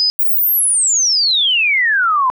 PallasPlus Chirp Test